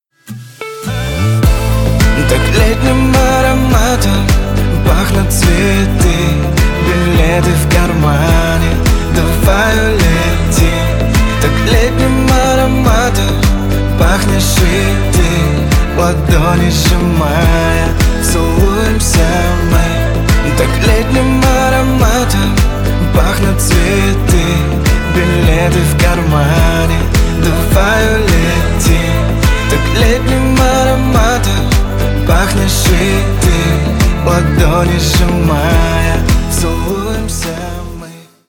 Нарезка припева на вызов